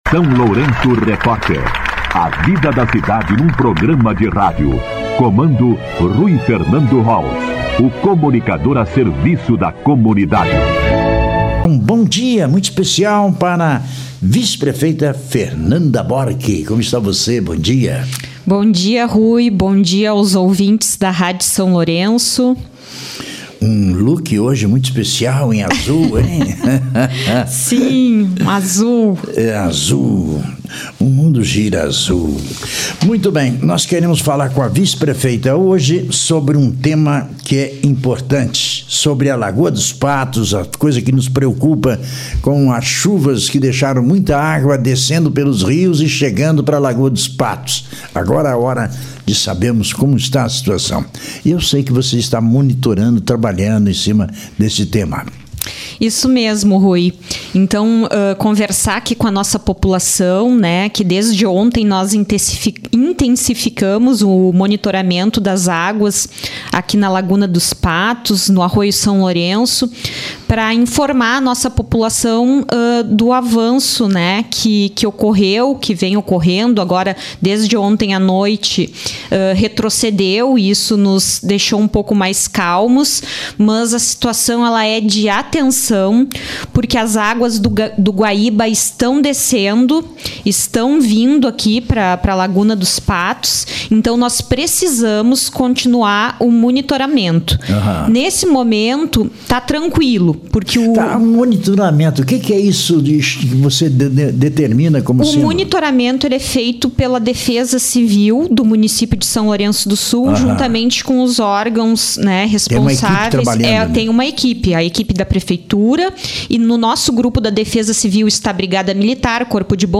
Entrevista com a Vice-prefeita Fernanda Bork